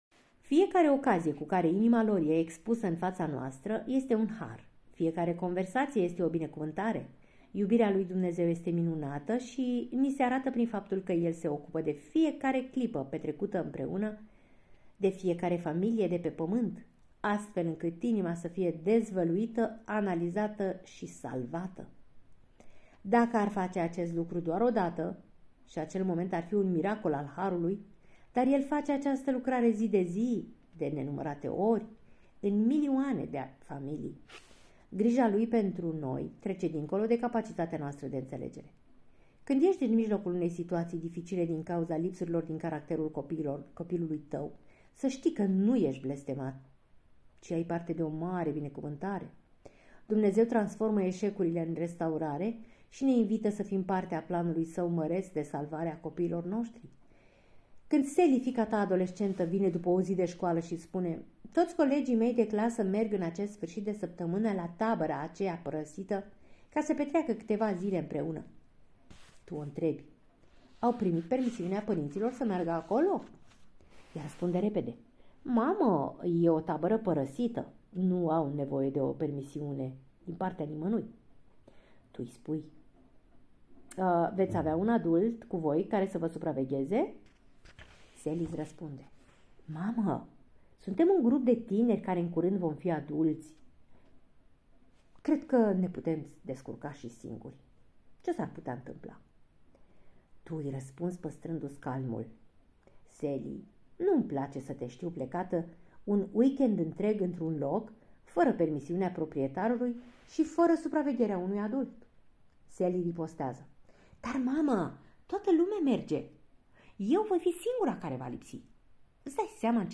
Acest podcast este a doua parte a celui de-al zecelea capitol al cărții " Pentru părinți - 14 principii care îți pot schimba radical familia " de la Paul David Tripp.